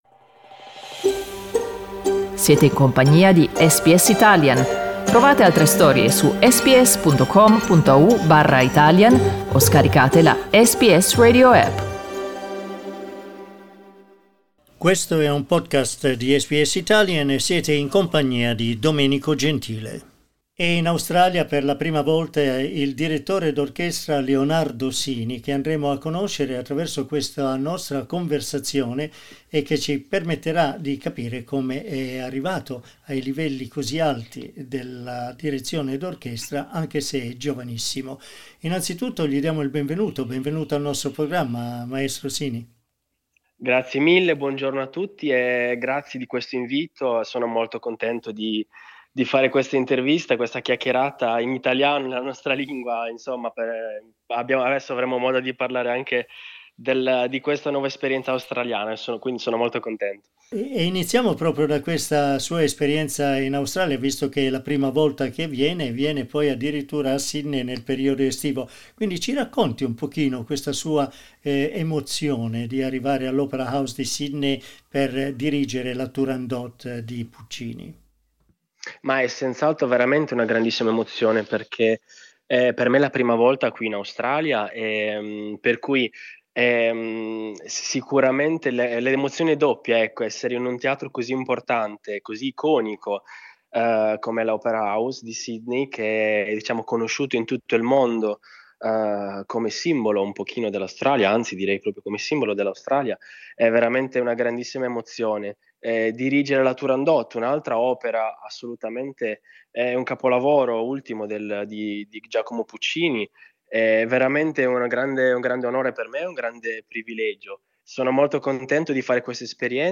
In questa conversazione con SBS Italian racconta anche di come si è avvicinato alla musica e della sua folgorante carriera.